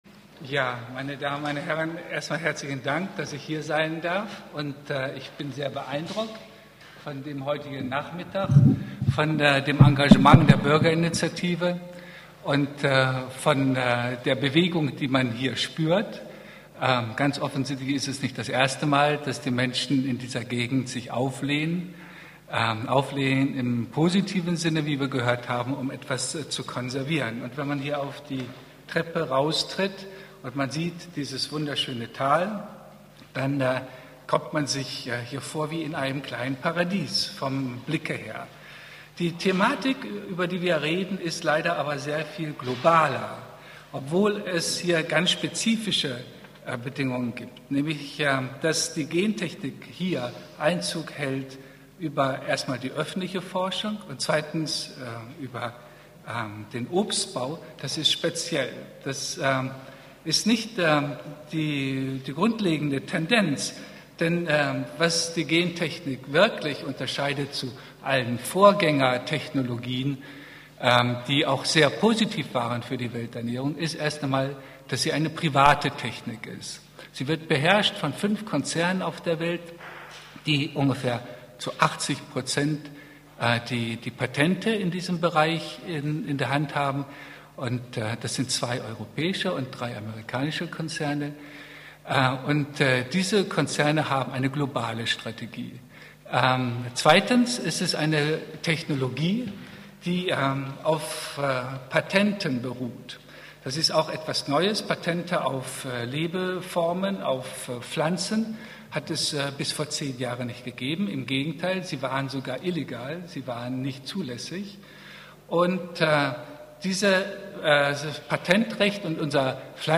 Einzelne Beiträge der Veranstaltung können als mp3-Datei runterladen werden.